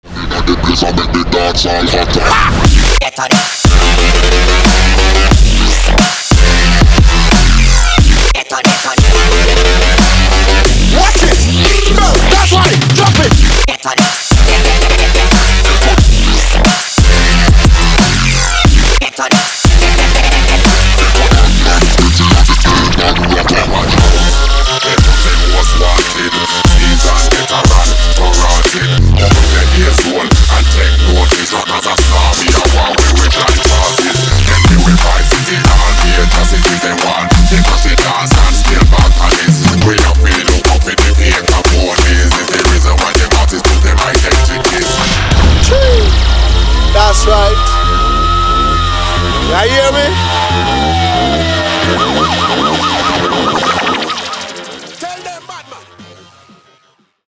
Дабстеп рингтоны